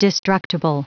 Prononciation du mot destructible en anglais (fichier audio)
Prononciation du mot : destructible